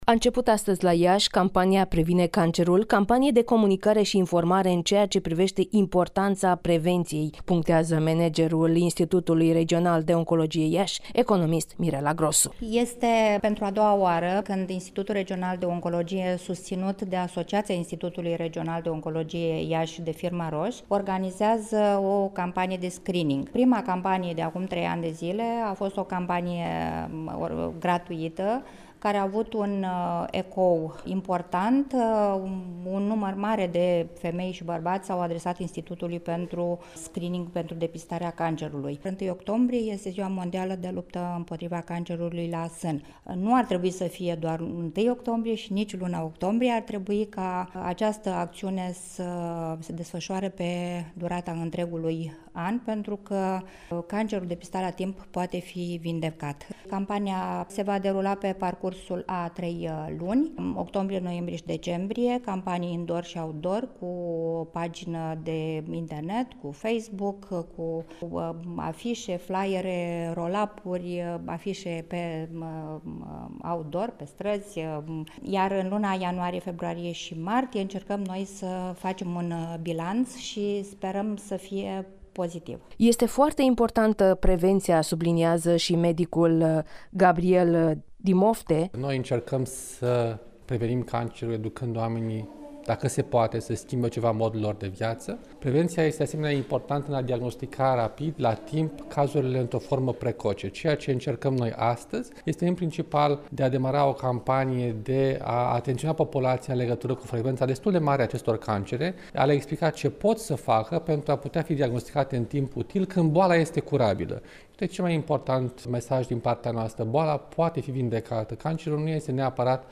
(REPORTAJ) Institutul Regional de Oncologie din Iași a lansat astăzi campania de promovare a screening-ului pentru depistarea cancerului